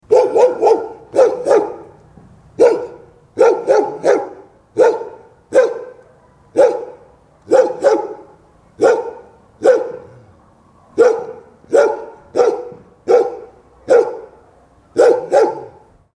دانلود صدای سگ خطرناک و آماده حمله از ساعد نیوز با لینک مستقیم و کیفیت بالا
جلوه های صوتی